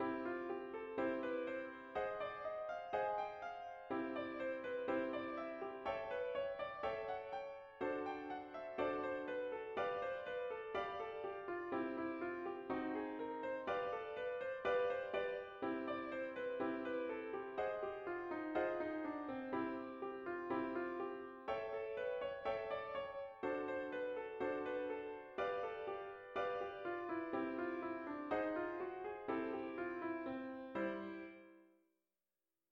Here is an example of an improvised solo done in a diatonic fashion using the same song.
DIATONIC SOLO #4
Sequence- Scale Improvised
Both the Motivic sequence and the diatonic forms of improvisation given here are very academic sounding but unfortunately many less gifted practitioners of this art form do sound like mathematicians endeavoring to play jazz.